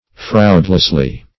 -- Fraud"less*ly , adv.
fraudlessly.mp3